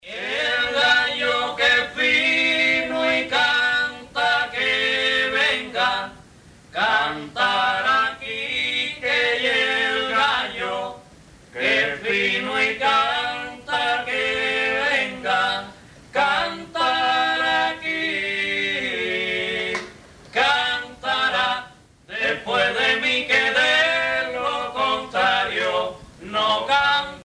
Punto esquinero
Pièces musicales tirées de la Parranda Tipica Espirituana, Sancti Spiritus, Cuba
Pièce musicale inédite